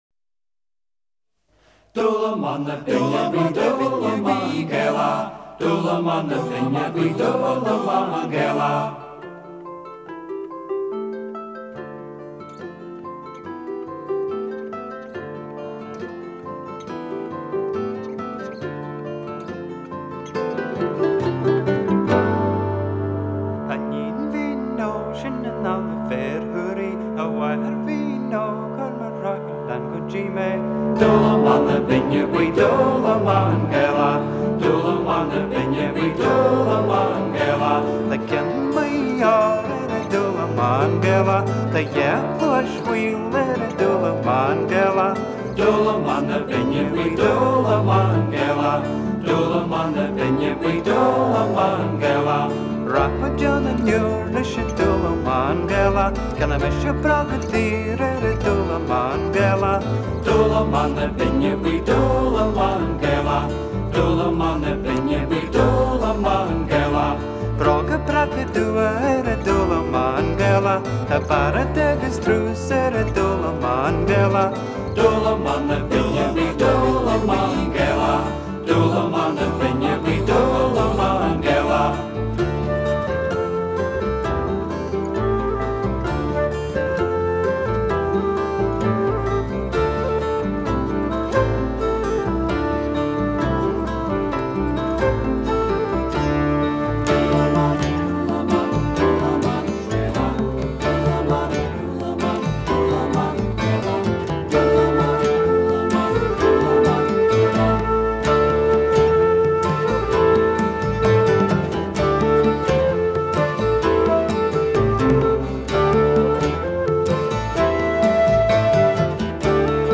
They needed a big production track to open their new album.